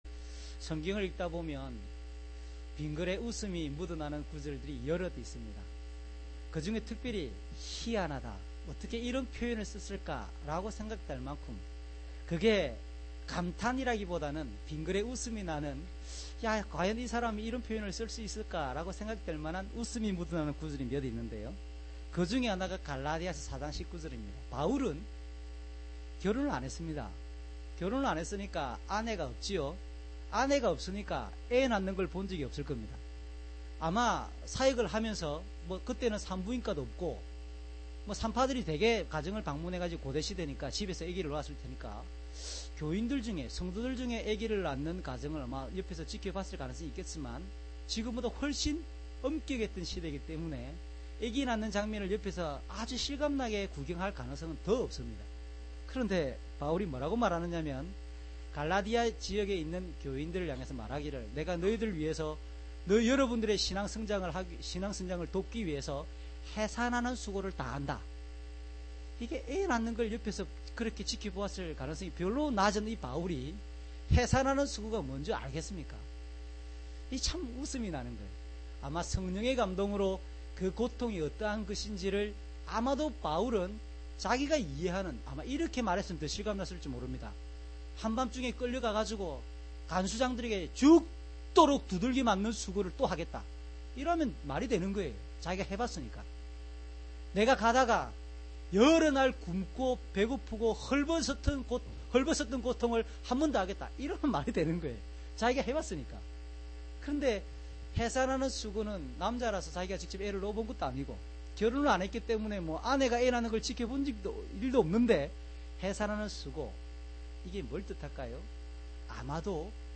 주일설교 - 09년 12월 13일 "가장 크고 놀라운 선물을 받았습니다."